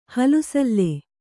♪ halu salle